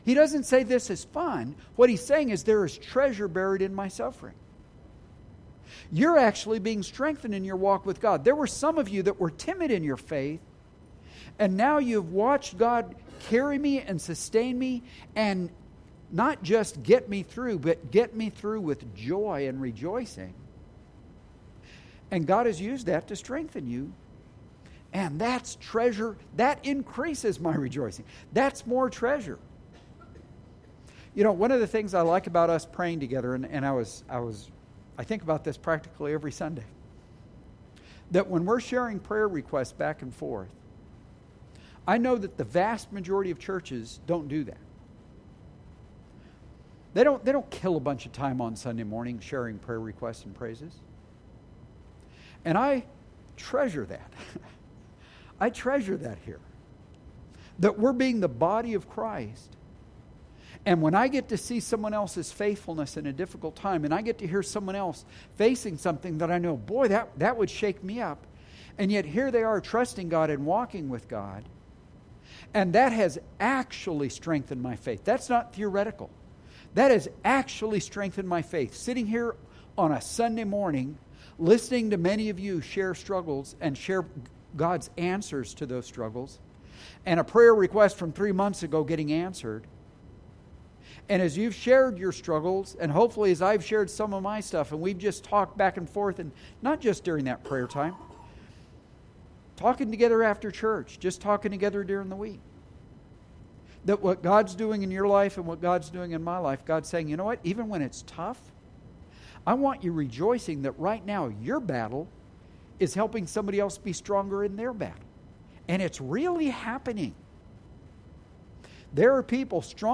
Sorry, the begining of the sermon was cut off.